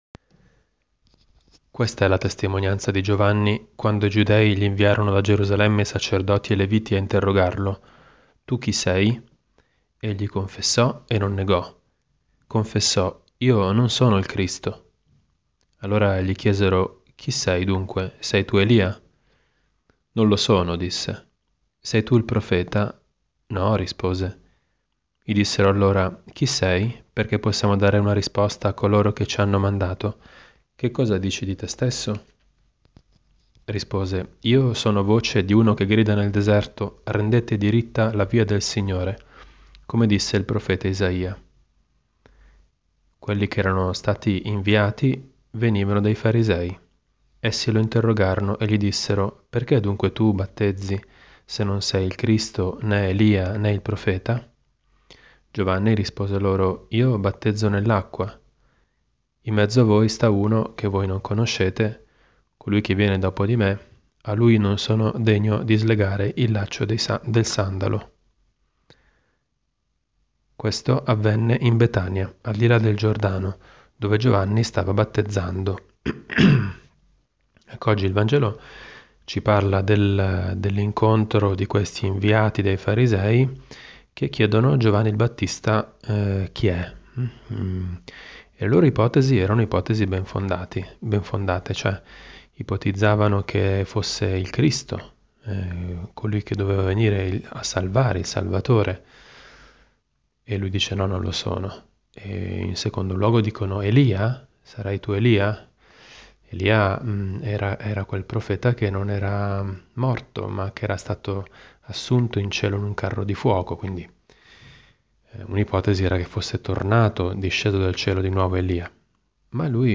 Commento al vangelo (Gv 1,19-28) di martedì 2 gennaio 2018, ferie di Natale.